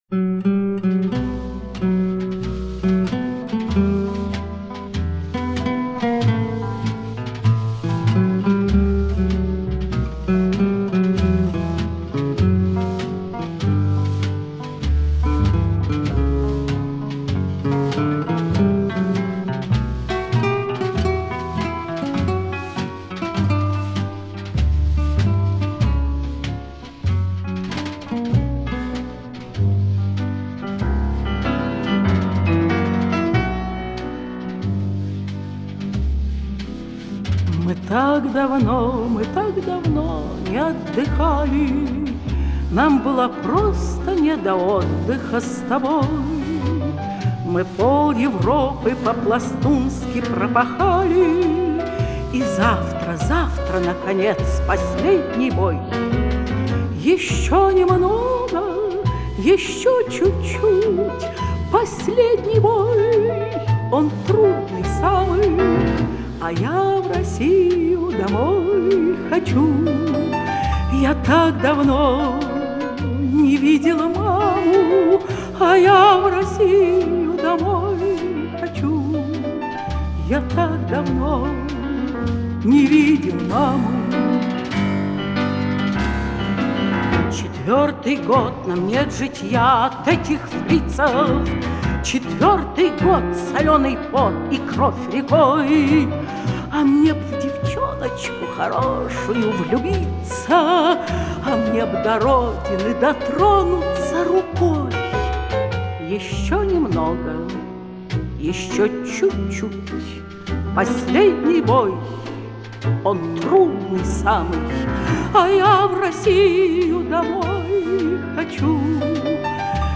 Её исполнению присуща глубокая задушевность.